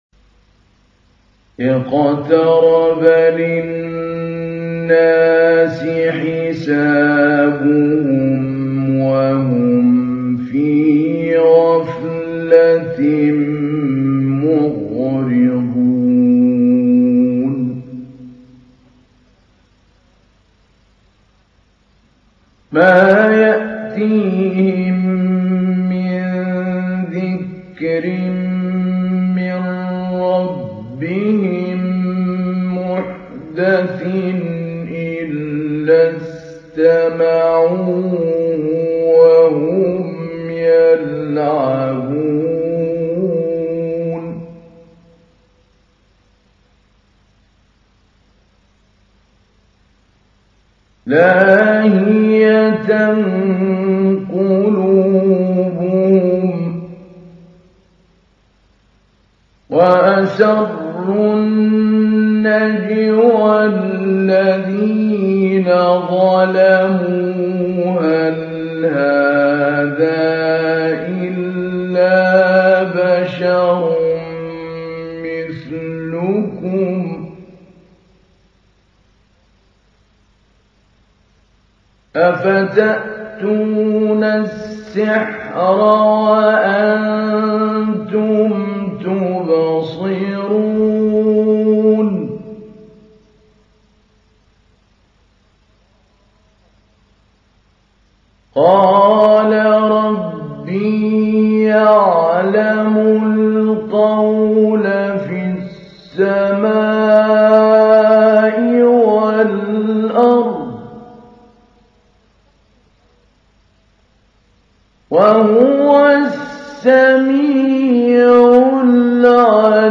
تحميل : 21. سورة الأنبياء / القارئ محمود علي البنا / القرآن الكريم / موقع يا حسين